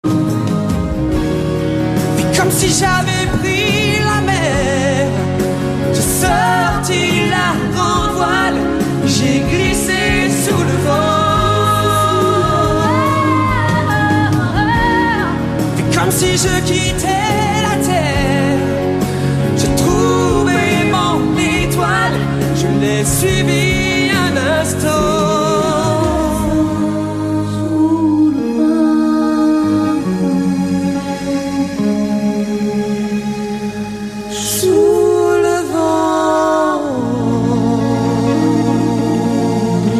live
дуэт